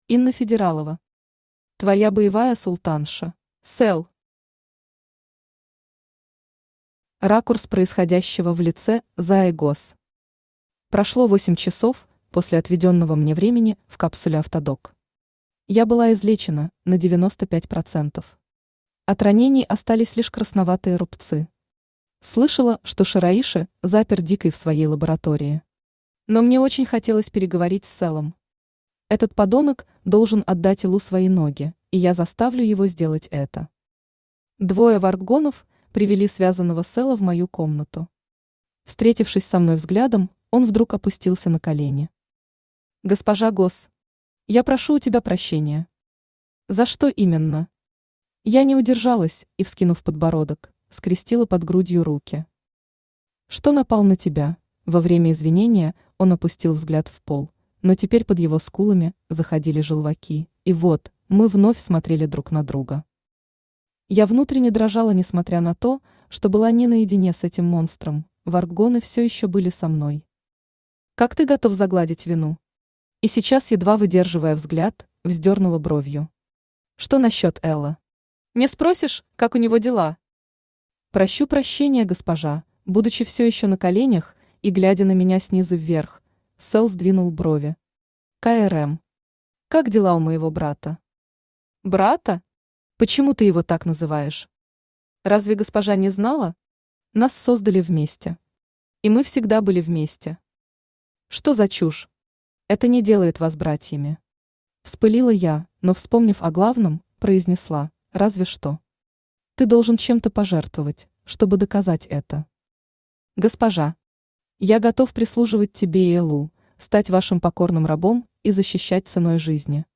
Аудиокнига Твоя боевая султанша | Библиотека аудиокниг
Aудиокнига Твоя боевая султанша Автор Инна Федералова Читает аудиокнигу Авточтец ЛитРес.